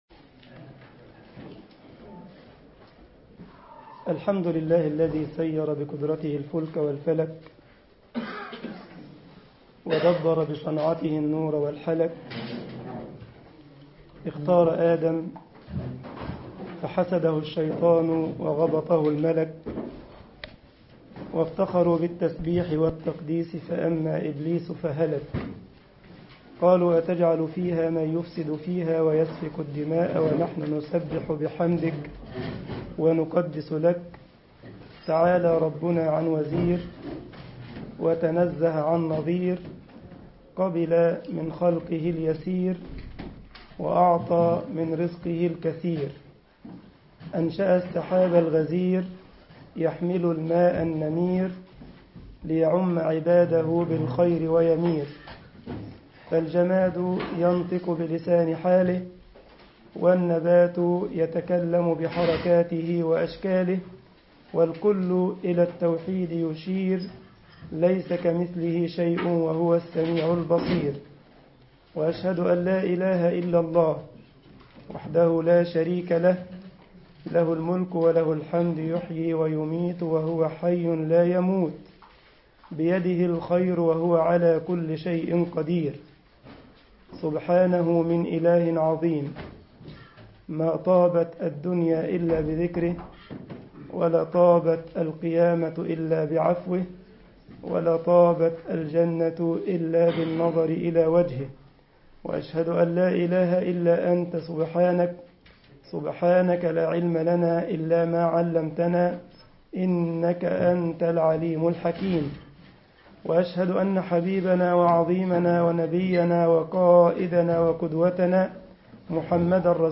مسجد الجمعية الاسلامية بالسارلند محاضرة